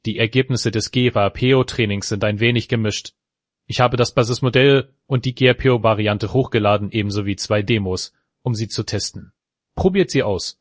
Here a sample with a random speaker:
On the random speaker the basemodel already produces good results after training it for 2 epochs and using it with a temperature of 1.